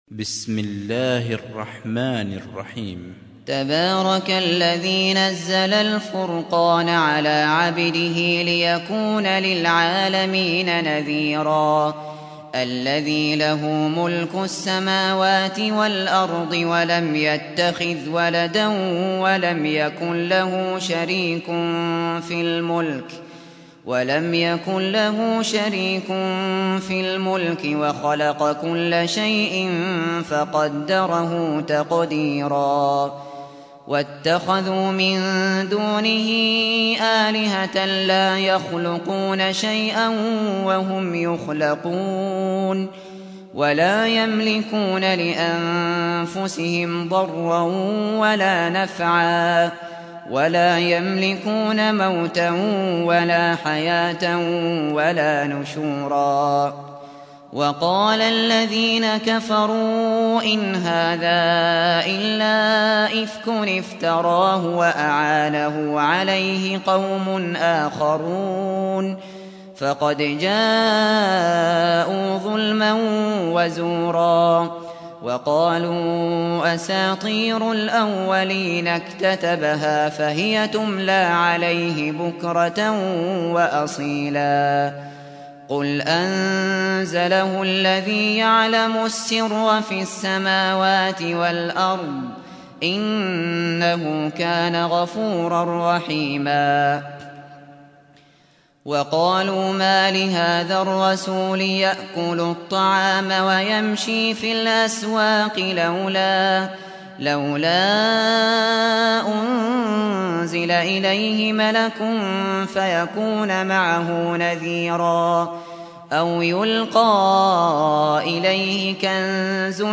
سُورَةُ الفُرۡقَانِ بصوت الشيخ ابو بكر الشاطري